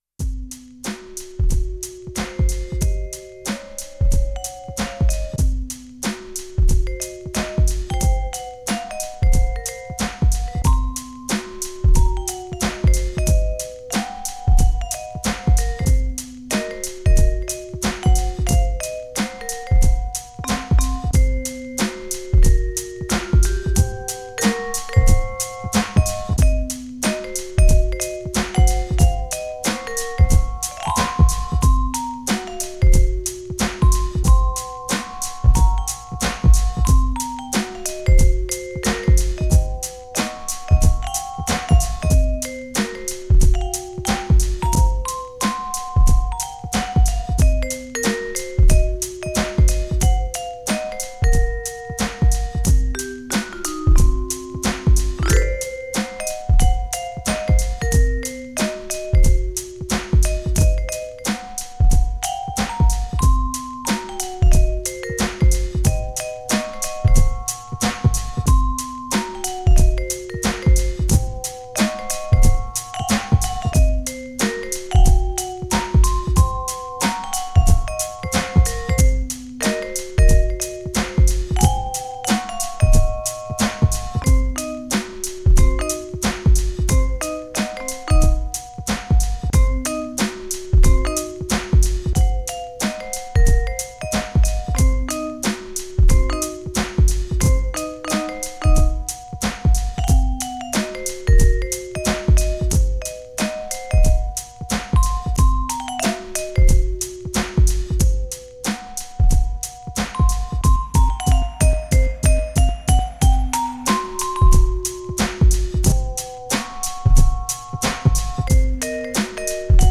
Tempo: 47 bpm / Datum: 12.09.2017
Jazz /Creative Commons License 4.0 / noncommercial use free